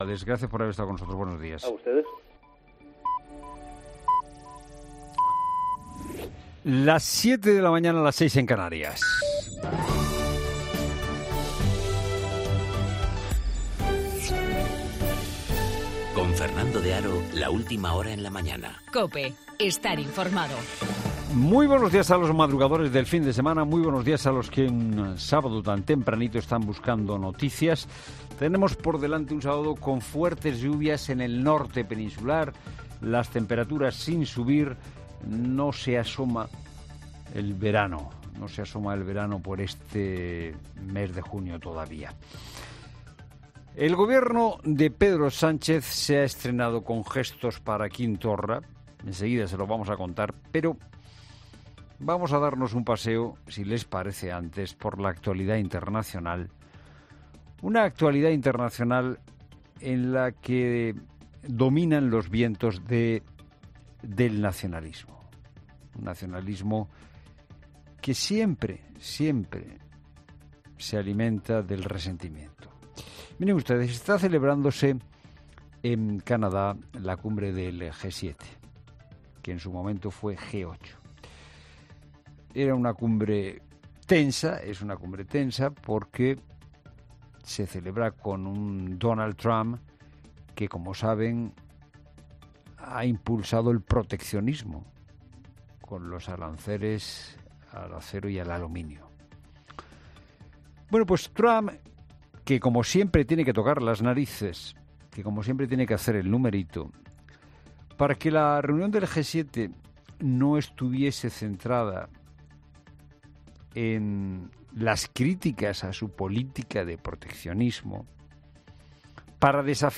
AUDIO: Toda la información y actualidad de la mano de Fernando de Haro.